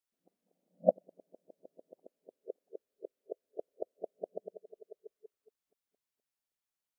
creaking_heart_idle2.ogg